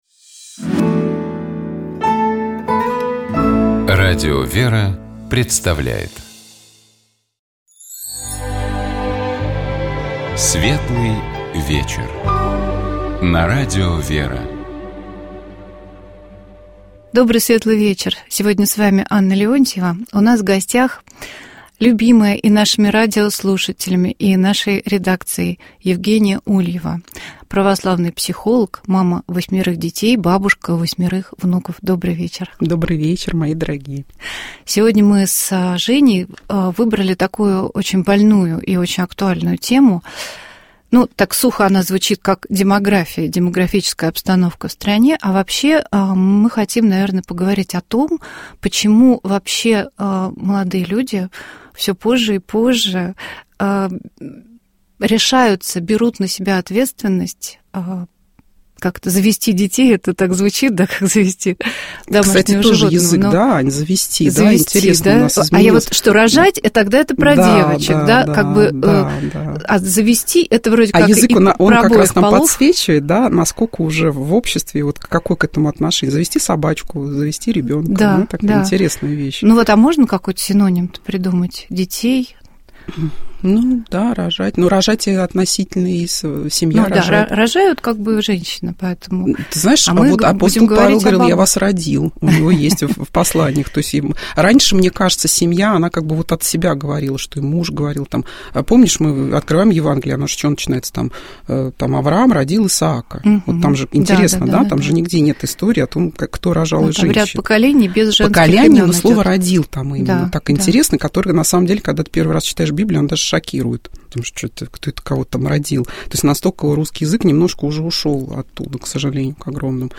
У нас в гостях была психолог